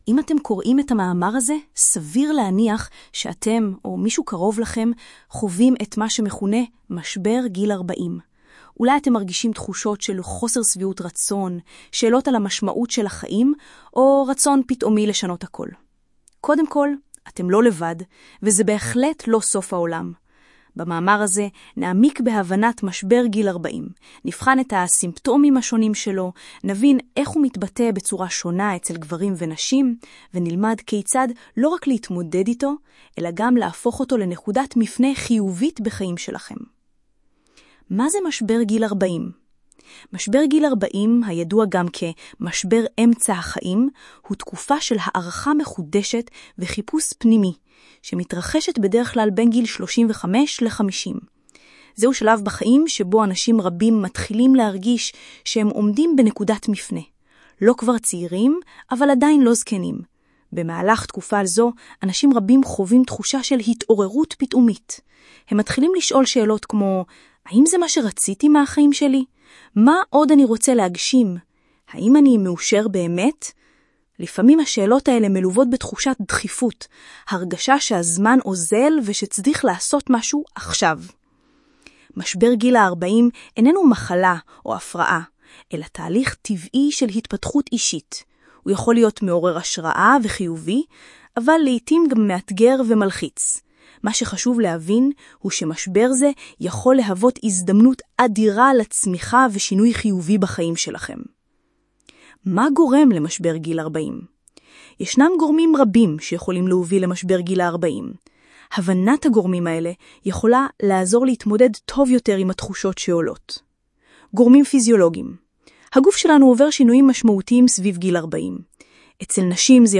גרסת שמע של המאמר 'משבר גיל 40' מאתר ניר זר, מוקרא באמצעות קול ממוחשב (AI)